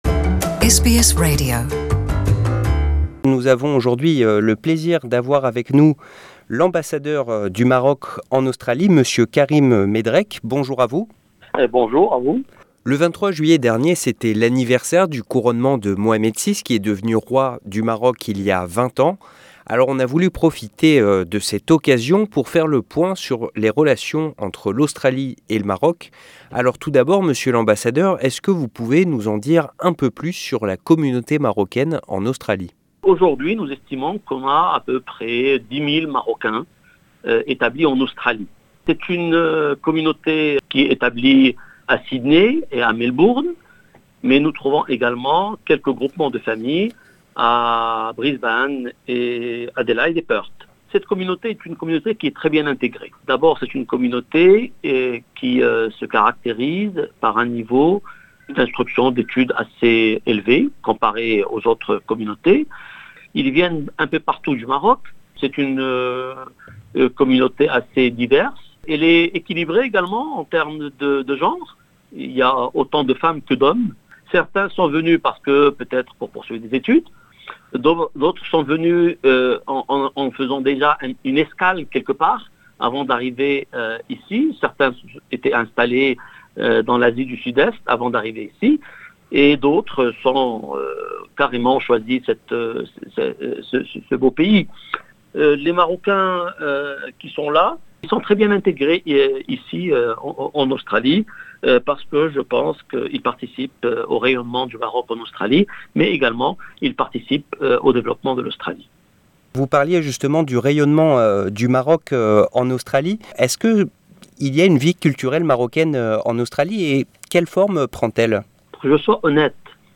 Interview avec l'ambassadeur du Maroc en Australie